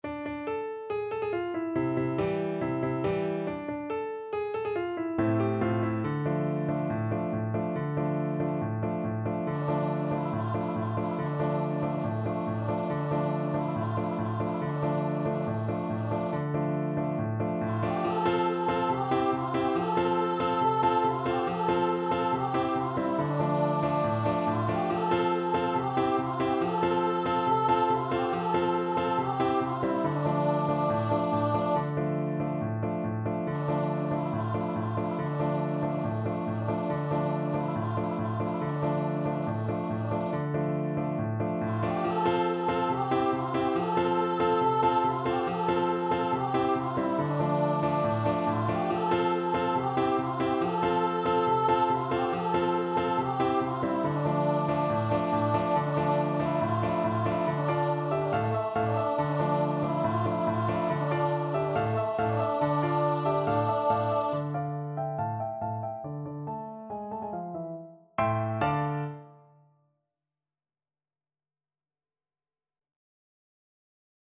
Voice
4/4 (View more 4/4 Music)
D minor (Sounding Pitch) (View more D minor Music for Voice )
Very Fast =c.140
Israeli